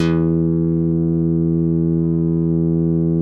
MICROFUNK E3.wav